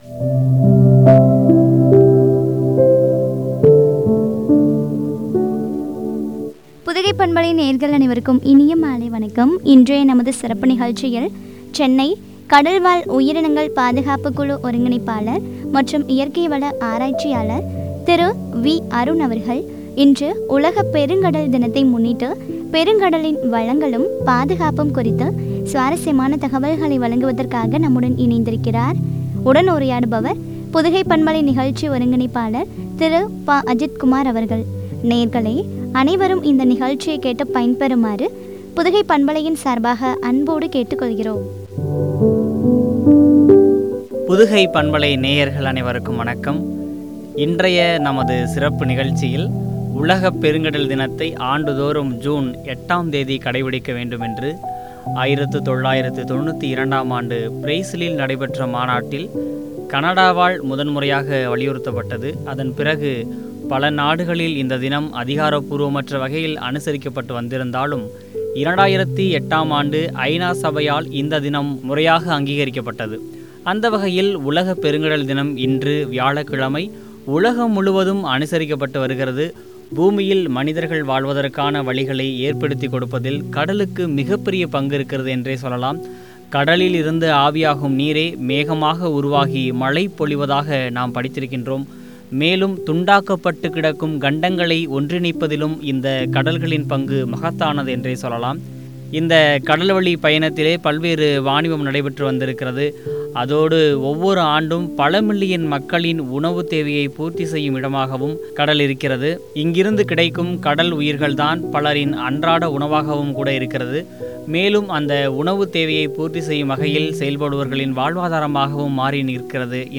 பாதுகாப்பும் குறித்து வழங்கிய உரையாடல்.